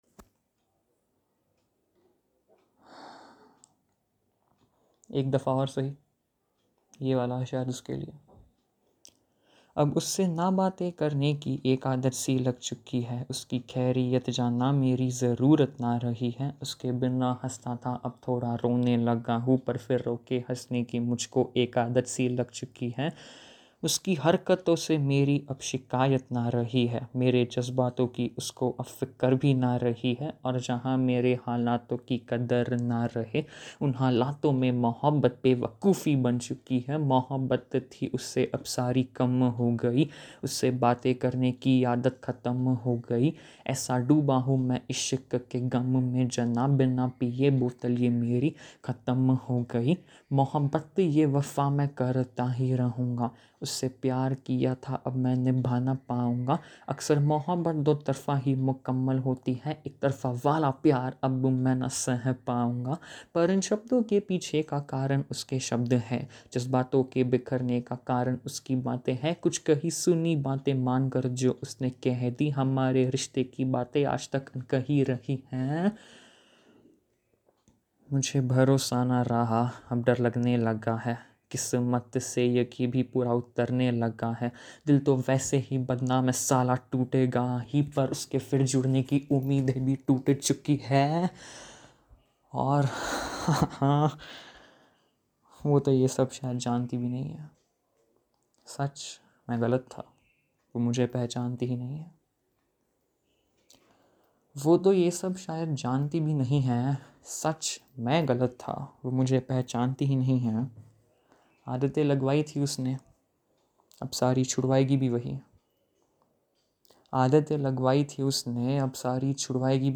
The first 2 are written in English, while the third one is a free verse, recited in Hindi.
As you know the drill, I always prefer to recite my pieces and hence, would request you to download/play the audio, simultaneously while reading the lyrics, for the best experience.